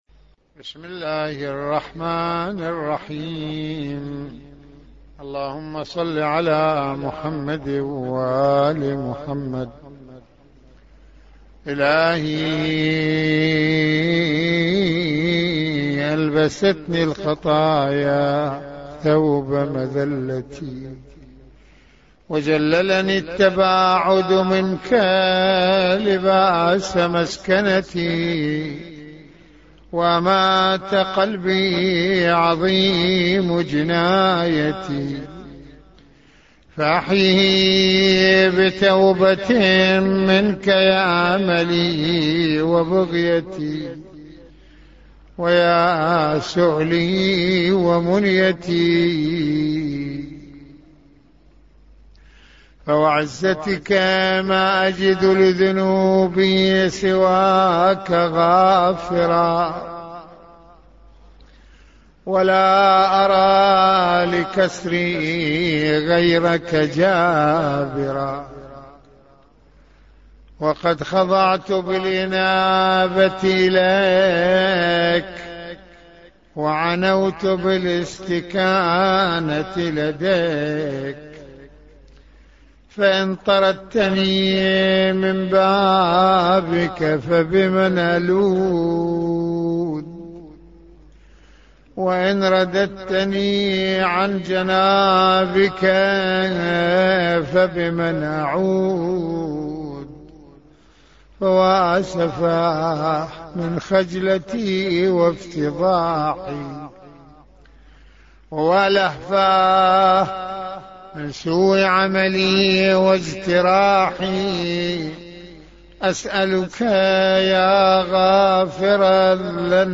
- استمع للدعاء بصوت سماحته
دعاء